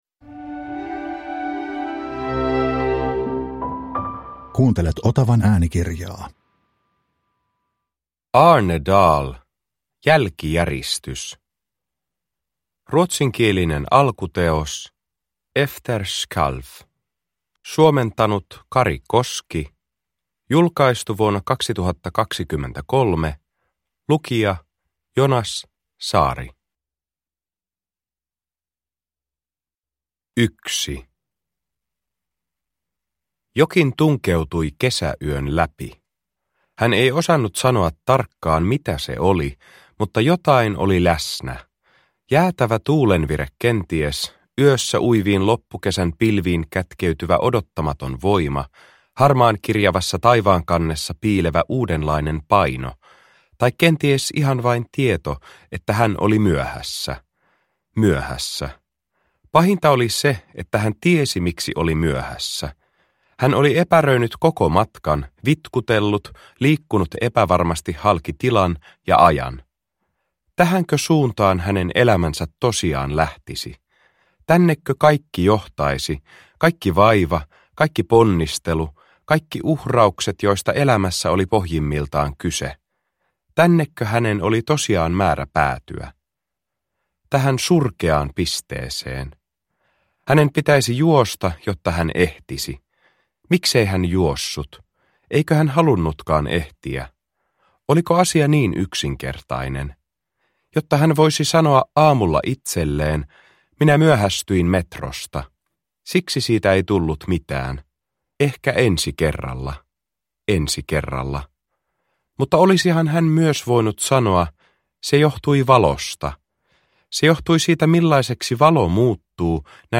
Jälkijäristys – Ljudbok – Laddas ner